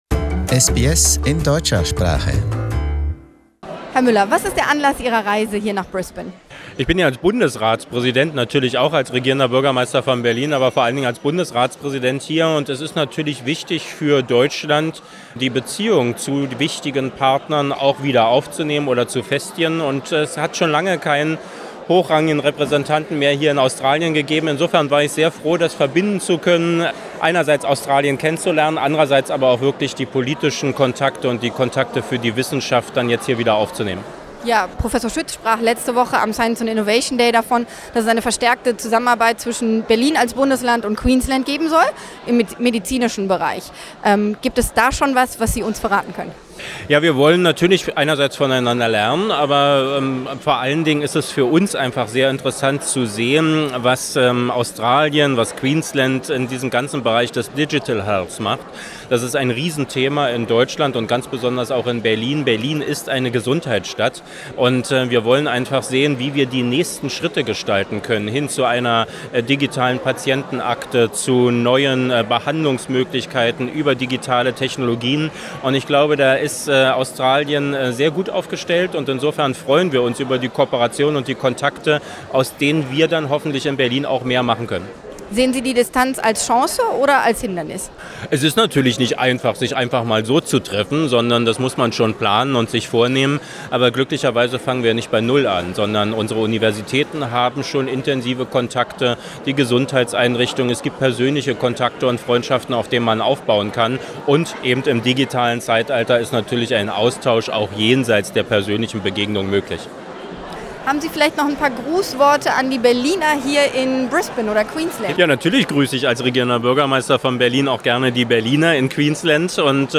Greetings from the Berlin Mayor
On the occasion of his visit to Brisbane, Berlin Mayor Michael Mueller sends his best wishes to all Australians who came from Berlin.